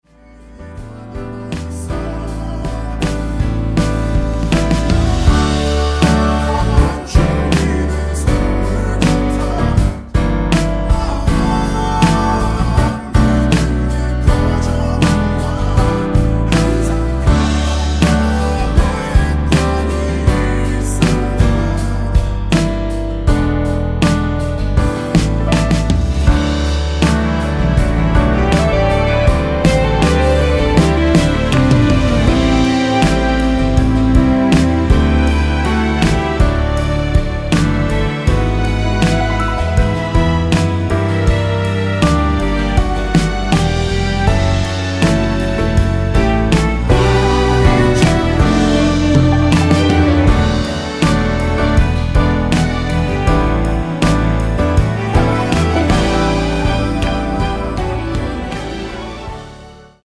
원키에서(-2)내린 2절 삭제 코러스 포함된 MR 입니다.(미리듣기및 아래의 가사 참조)
엔딩이 페이드 아웃이라 라이브 하시기 좋게 엔딩을 만들어 놓았습니다.
앞부분30초, 뒷부분30초씩 편집해서 올려 드리고 있습니다.